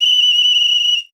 Foley Sports / Whistle / Ice Hockey Foul.wav
Ice Hockey Foul.wav